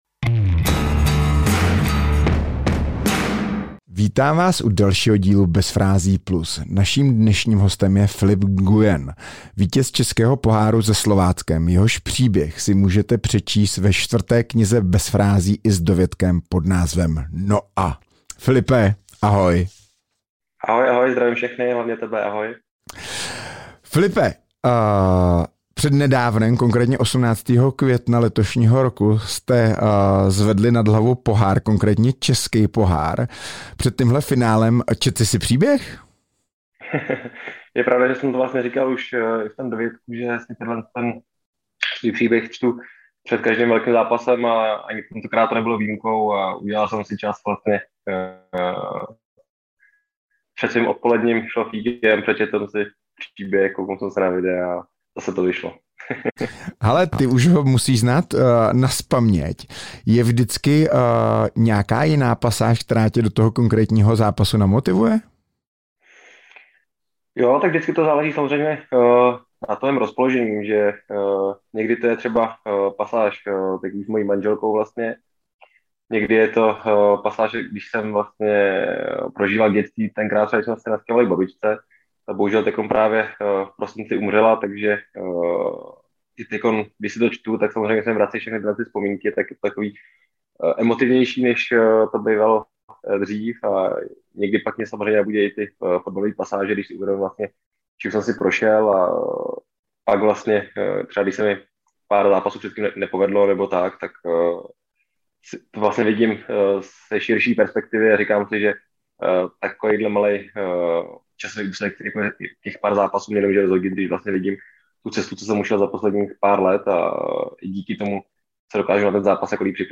Hostem dalšího dílu Bez frází+ je fotbalový brankář Filip Nguyen.
rozhovoru